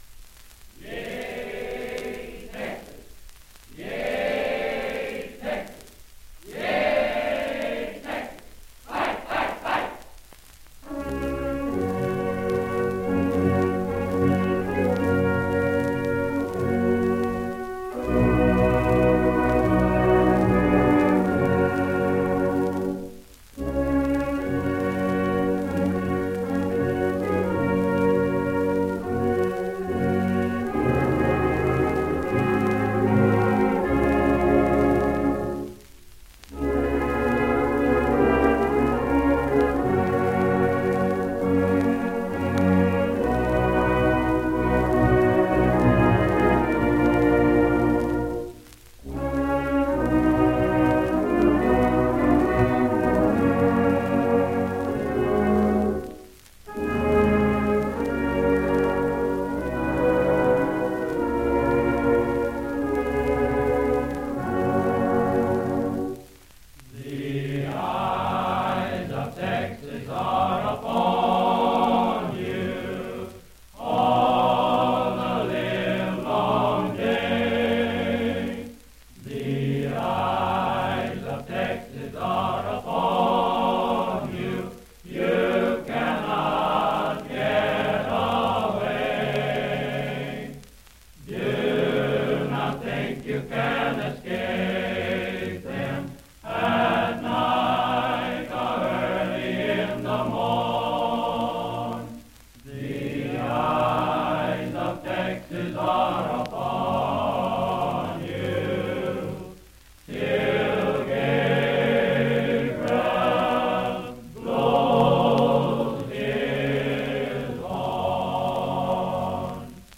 1928 Victrola Records.
The Eyes of Texas is performed at a slower tempo than what is usually heard today, and the song is introduced with a yell: “Yea Texas!
If you string together the parts where “Texas Fight!” is sung and eliminate the rest, you’ll hear the familiar Taps bugle call.
Unfortunately, the cheer is shouted quickly and hard to understand, though it turns out to be a slight variation from the original Rattle-de-Thrat yell created in 1896.
Recorded: May 20, 1928 in San Antonio, Texas
Instrumentation: 7 clarinets, 2 saxophones, 2 French horns, 7 cornets, 4 trombones, baritone horn, 2 tubas, 3 drums, bass drum, and tympani.
Men’s Chorus: 5 tenors, 4 second tenors, 4 baritones, and 4 basses.